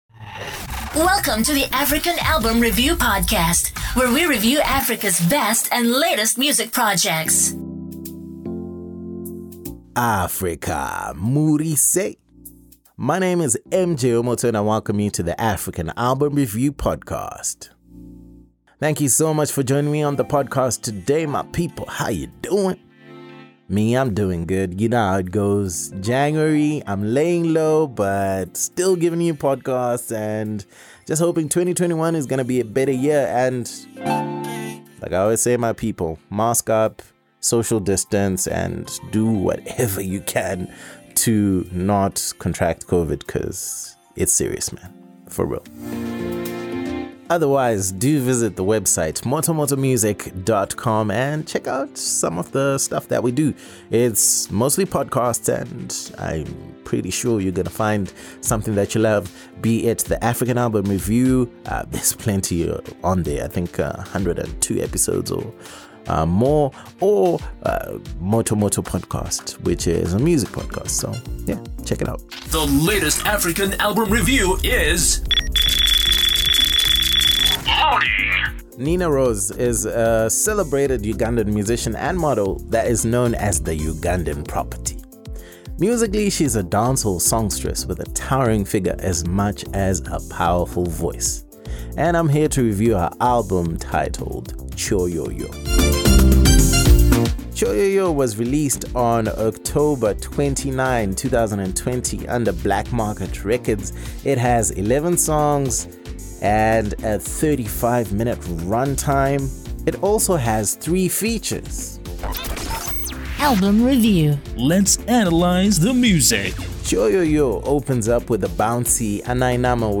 Nina Roz – Kyoyooyo ALBUM REVIEW Uganda